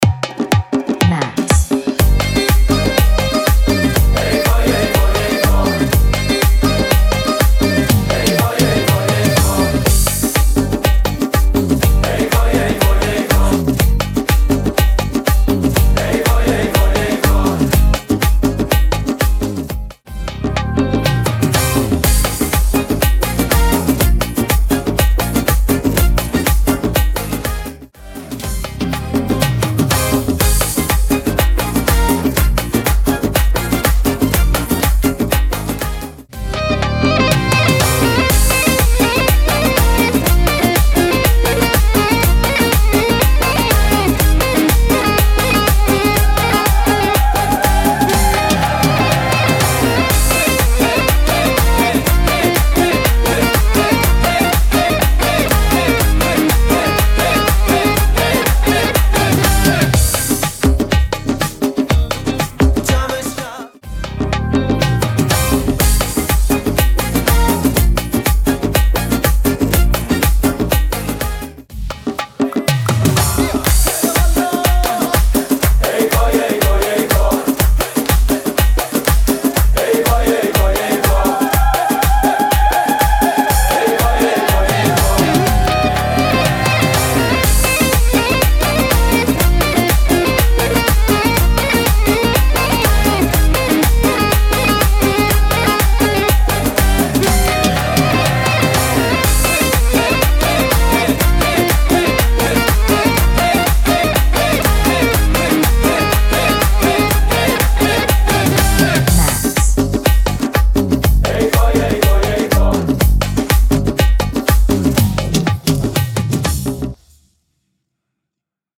ژانر: بی کلام
شاد